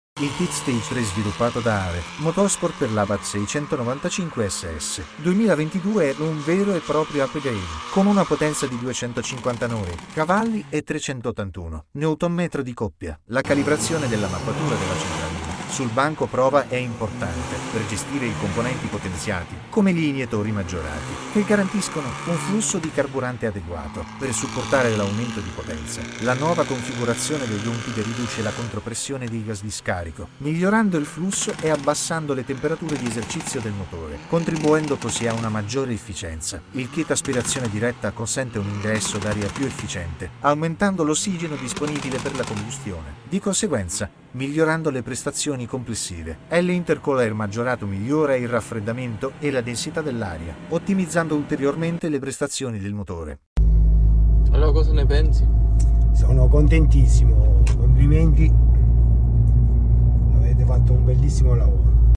😱259 🐎 Abarth 695 SS sound effects free download